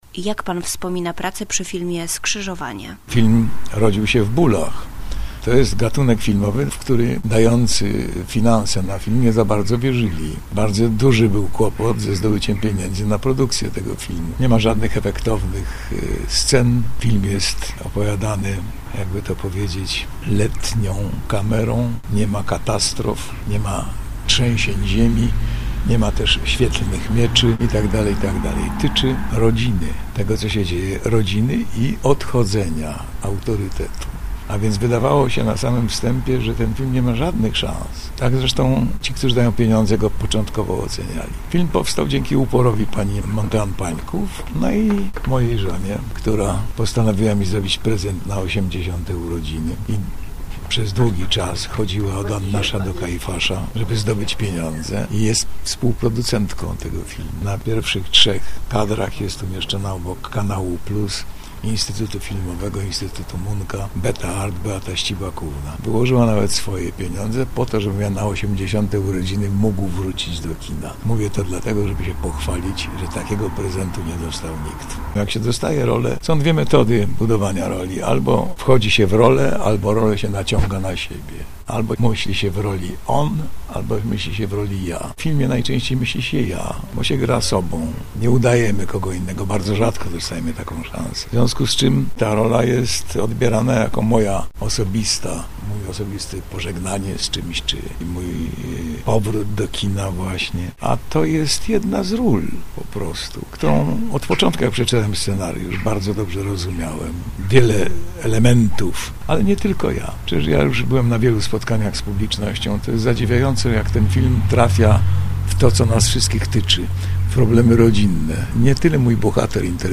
Aktor i reżyser Jan Englert odwiedził Konin podczas 71. edycji OKFA, czyli Ogólnopolskiego Konkursu Filmów Niezależnych im. profesora Henryka Kluby.